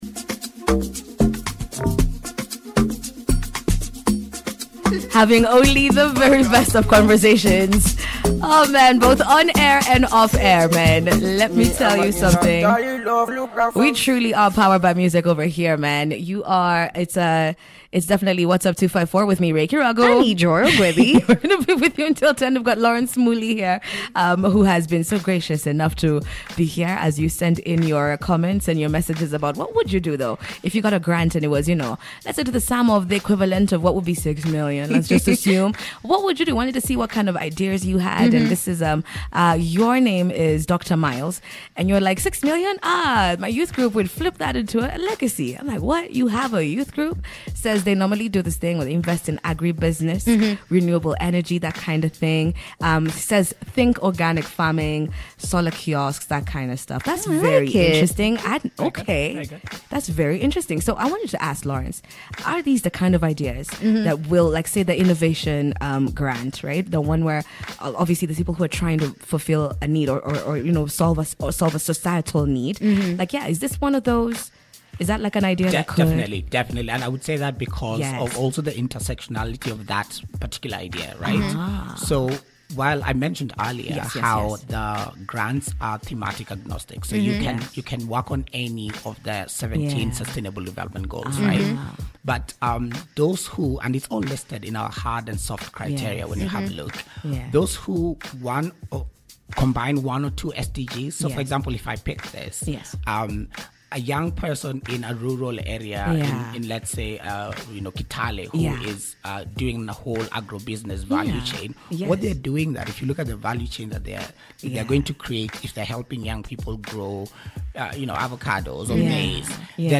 INTERVIEW-LINK-3.mp3